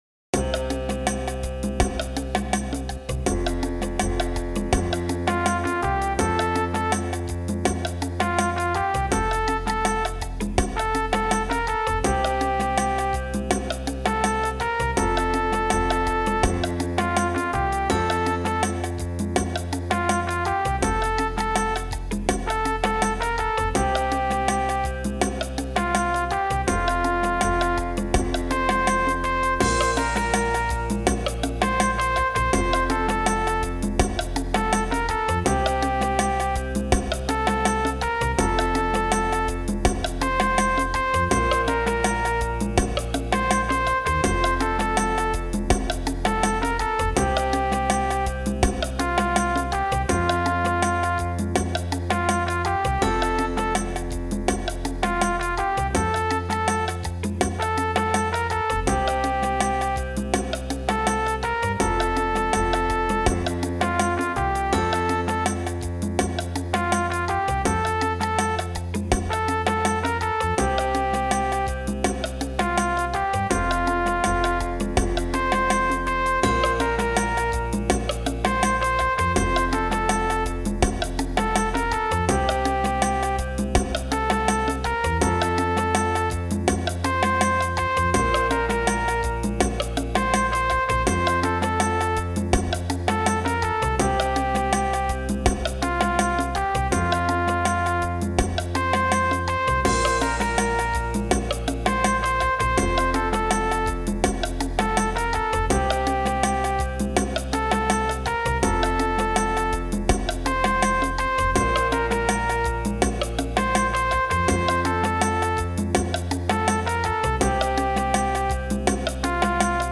communion song
The tempo should be ~165bpm.